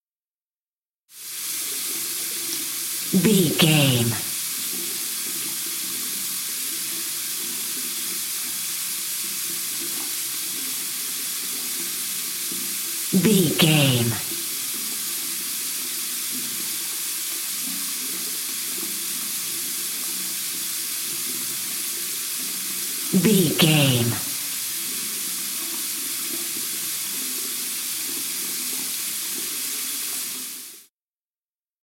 Bathroom handwasher
Sound Effects
urban